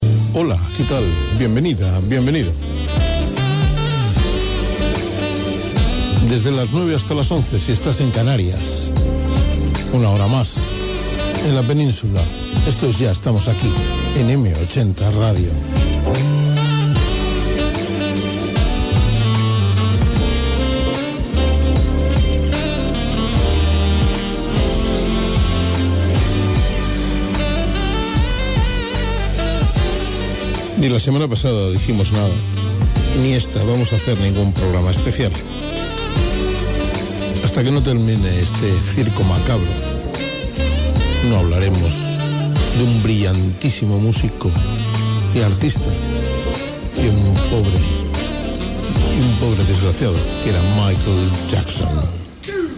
Presentació del programa Gènere radiofònic Musical